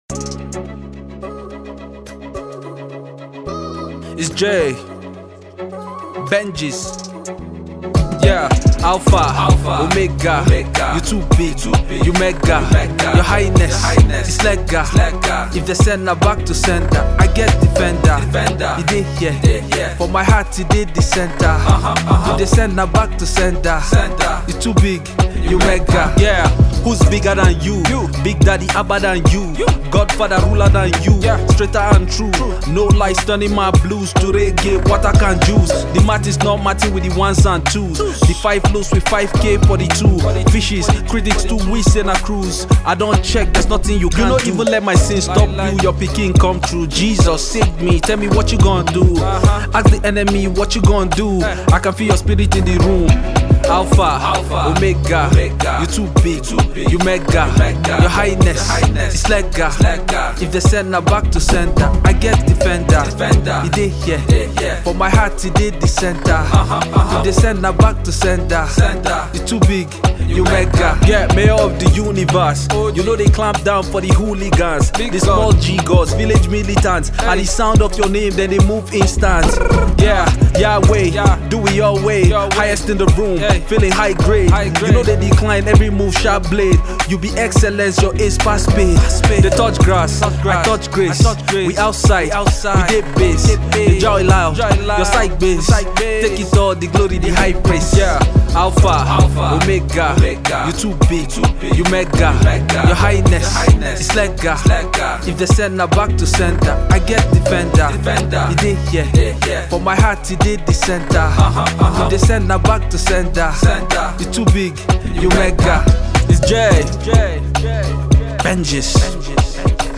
Afro beatmusic